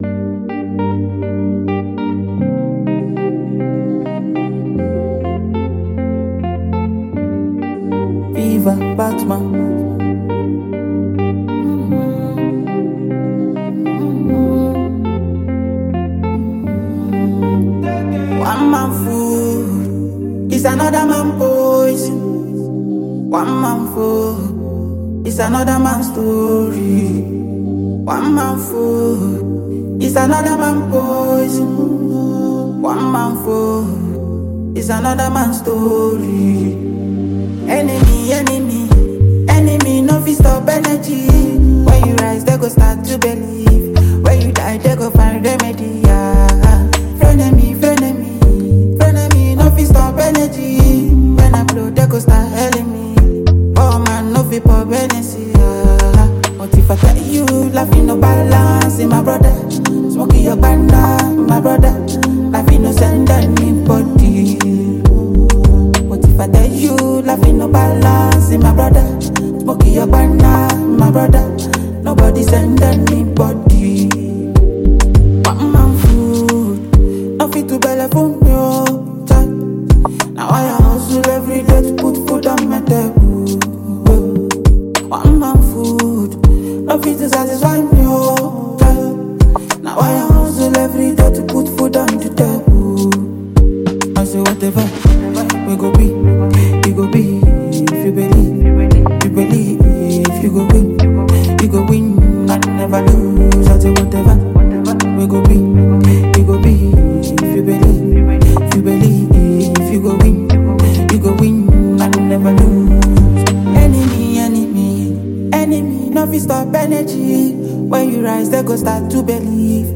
Nigerian sensational afrobeat artiste